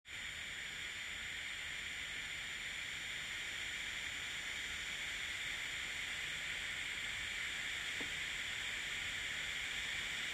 ただし、急速冷却時はファンの音が大きめなので注意しましょう。
▼冷却ファンの音（急速冷却時）
REDMAGIC8Pro-fan-Max.m4a